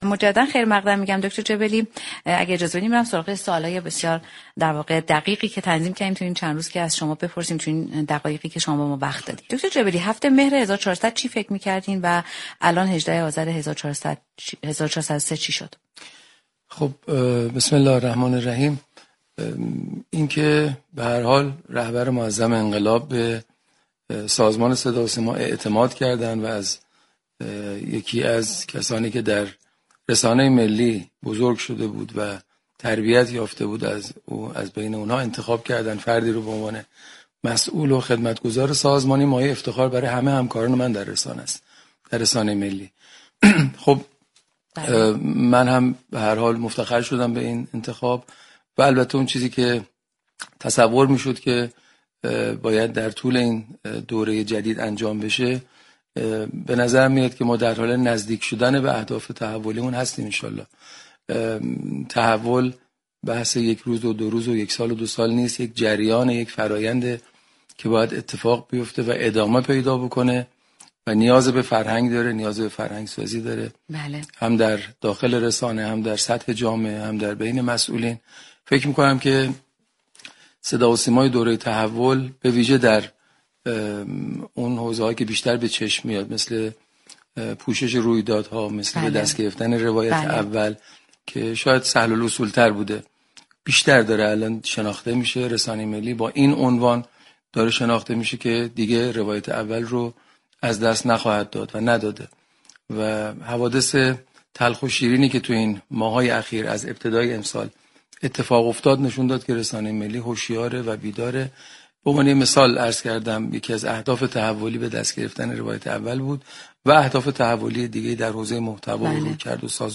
به گزارش پایگاه اطلاع رسانی رادیو تهران، پیمان جبلی رئیس سازمان صداوسیما صبح روز یكشنبه 18 اذرماه با حضور در استودیو پخش زنده رادیو تهران به تعدادی از پرسش های برنامه صبحگاهی «بام تهران» پاسخ داد.